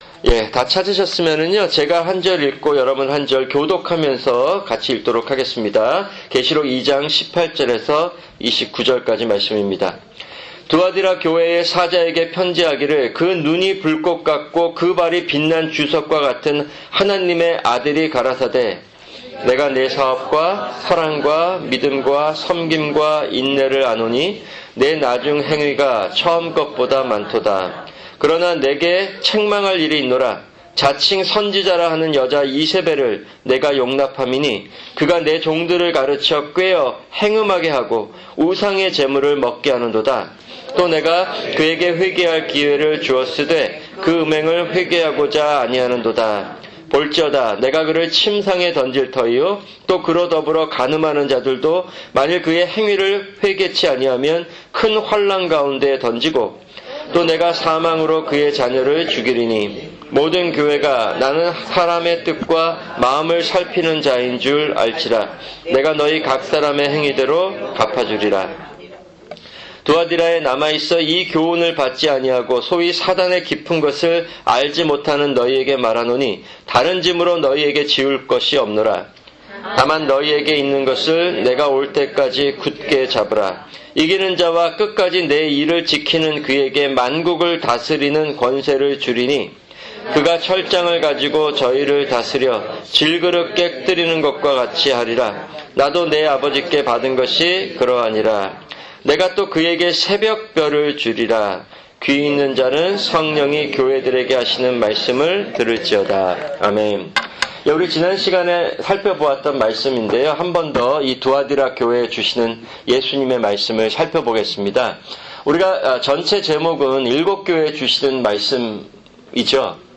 [금요 성경공부] 일곱 교회(17)